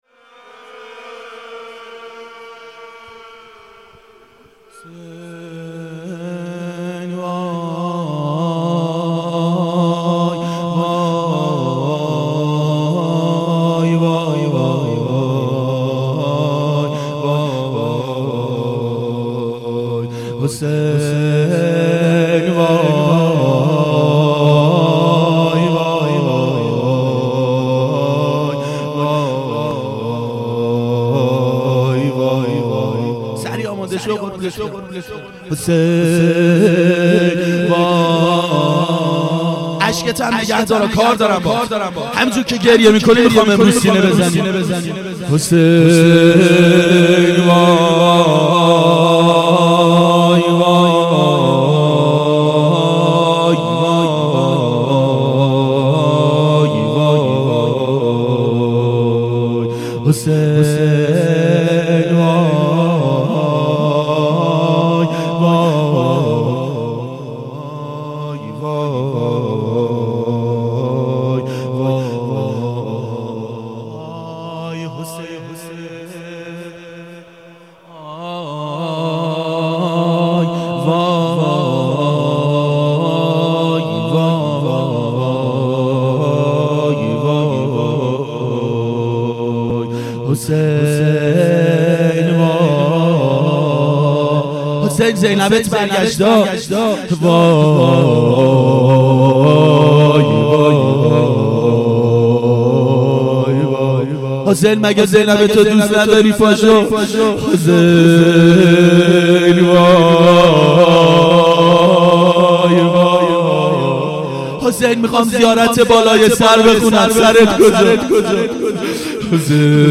ظهر اربعین 1391 هیئت شیفتگان حضرت رقیه سلام الله علیها